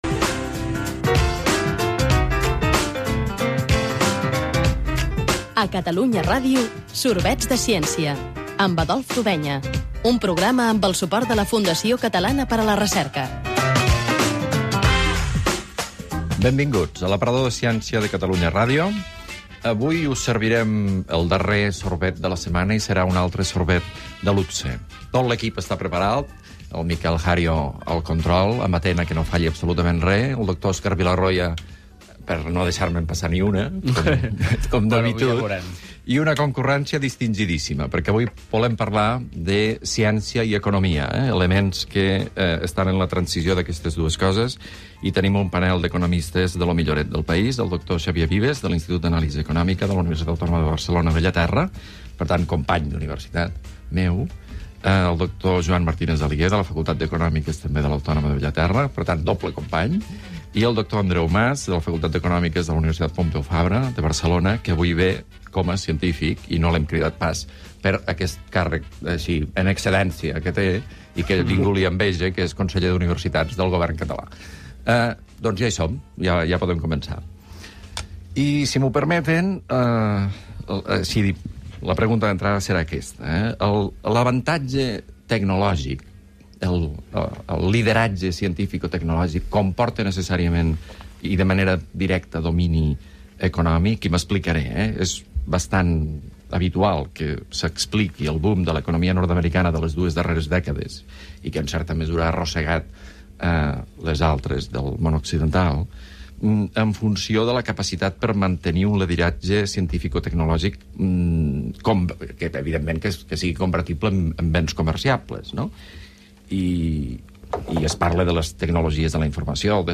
Careta del programa, presentació de l'espai dedicat a la ciència, la tecnologia i l'economia
Gènere radiofònic Divulgació